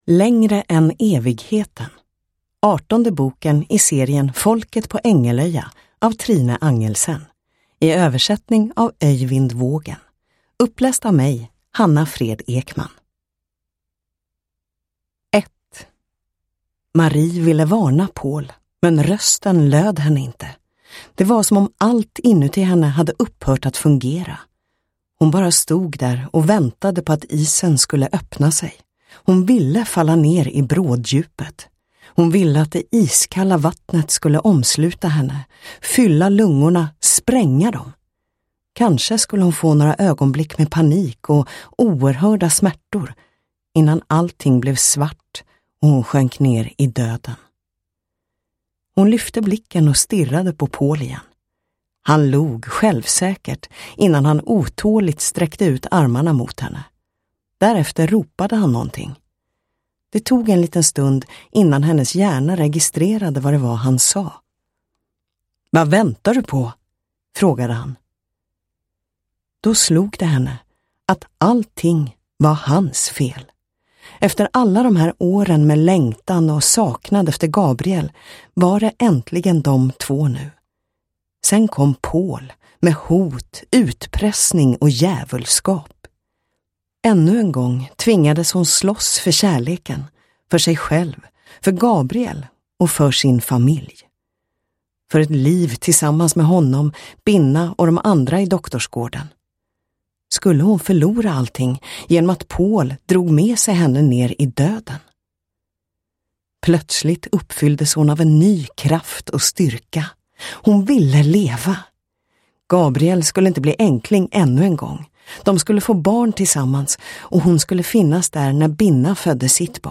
Längre än evigheten – Ljudbok – Laddas ner